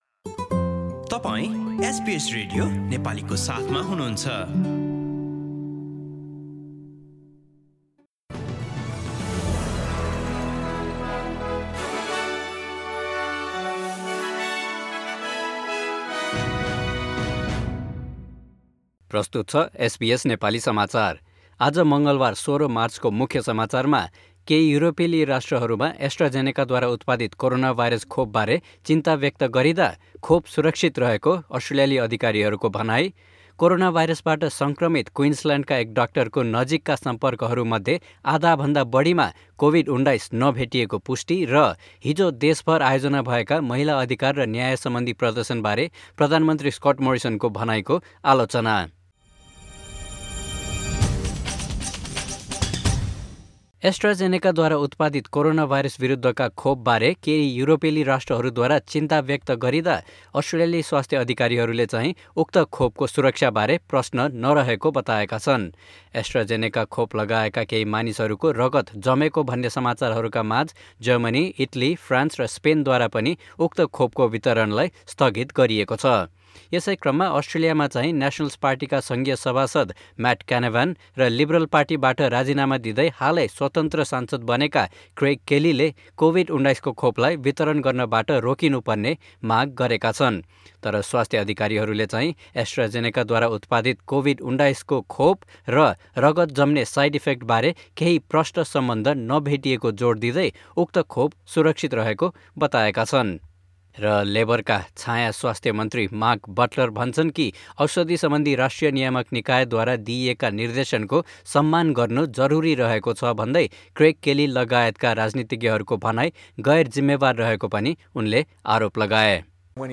Listen to latest news headlines from Australia in Nepali.